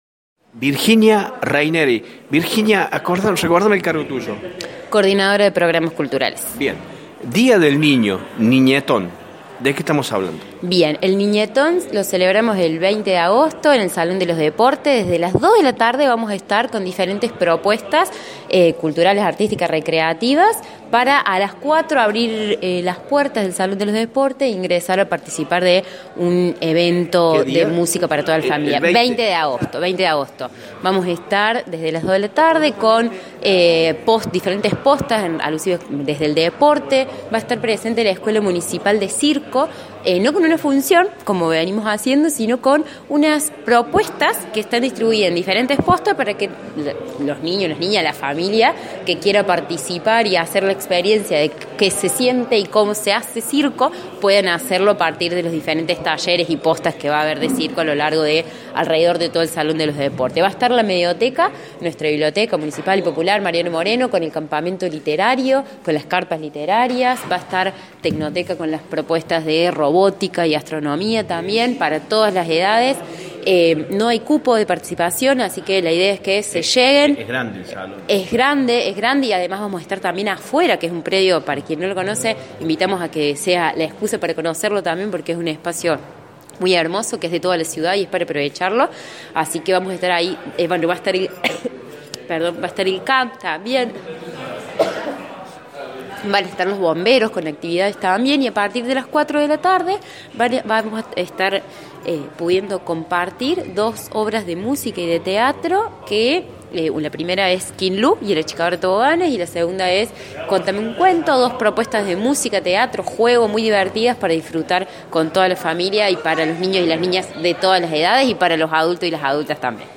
El municipio de Villa María ha dado a conocer una nueva edición del Niñetón, el evento infantil que llenará de juegos y actividades la ciudad en honor al Día de las Infancias. En una rueda de prensa conjunta, Héctor Muñoz, Jefe de Gabinete, y Sebastián Panero, presidente del Ente Villa María Deporte y Turismo, revelaron los detalles de esta iniciativa que se llevará a cabo el domingo 20 de agosto en el Salón de los Deportes a partir de las 14 horas.